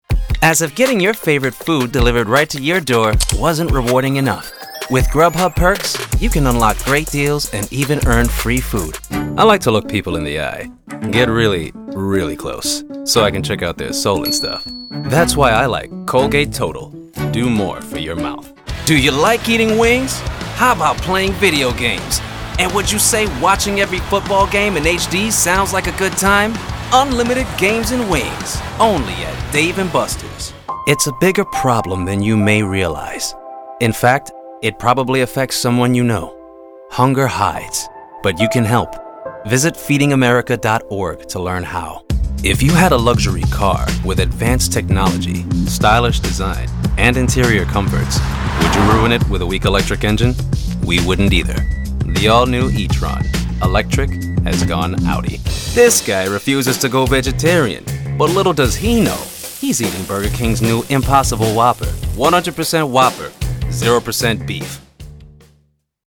Commercial Reel
I use a Deity S-mic 2 Shotgun Mic with a Scarlett SOLO FocusRite preamp, and a padded padded area for sound regulation.